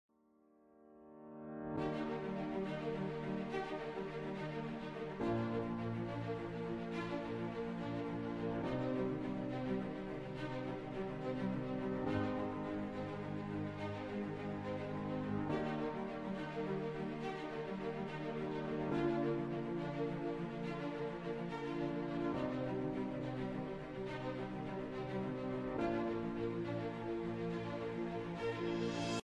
bg_music_intro.mp3